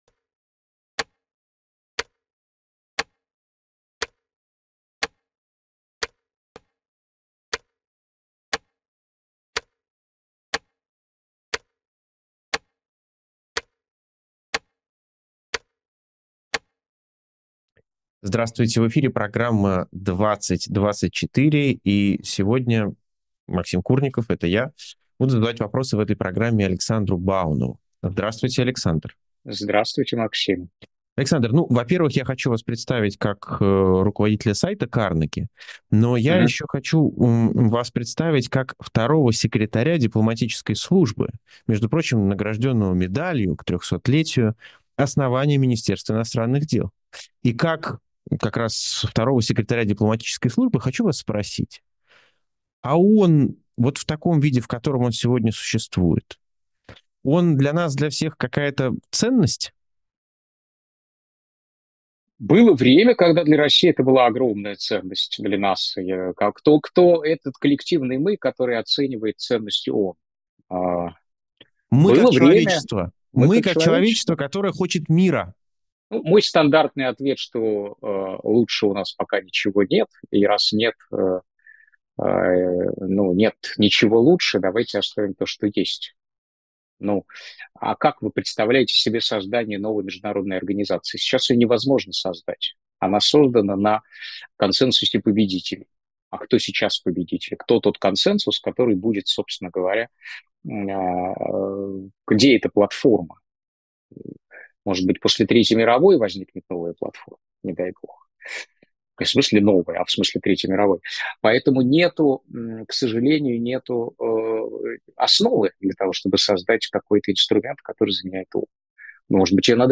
«2024» с Александром Бауновым Александр Баунов журналист Максим Курников руководитель проекта «Эхо», журналист Северный кореец, конечно, не может ни пойти наемником, ни пойти добровольцем.